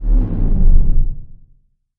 Descarga de Sonidos mp3 Gratis: maquina 3.
maquina_3.mp3